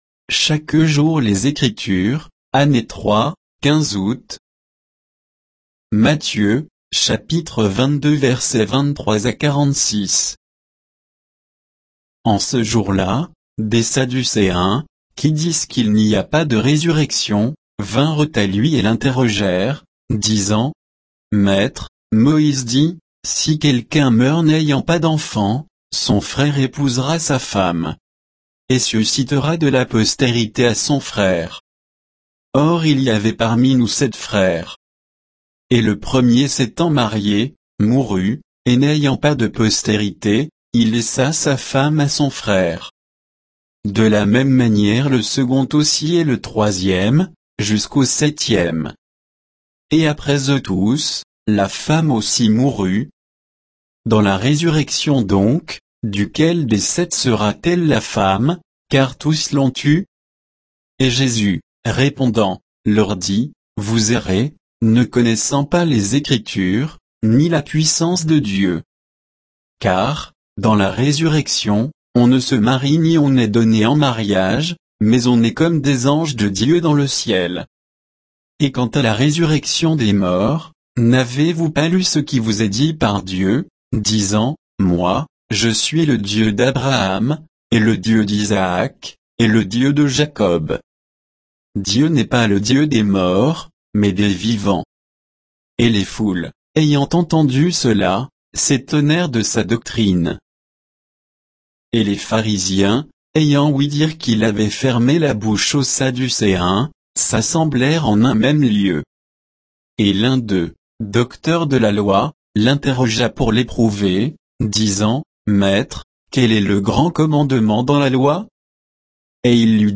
Méditation quoditienne de Chaque jour les Écritures sur Matthieu 22, 23 à 46